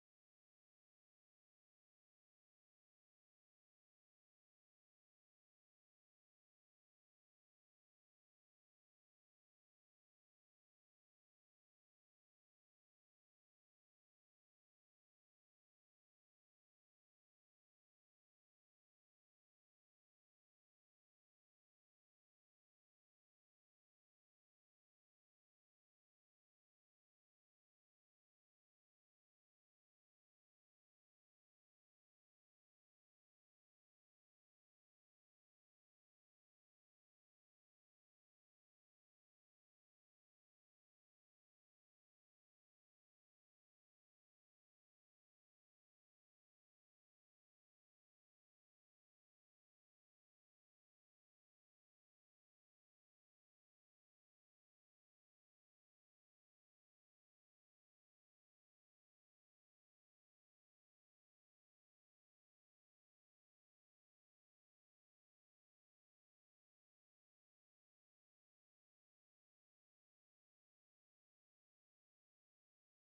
Menominee Homecoming Powwow August 2023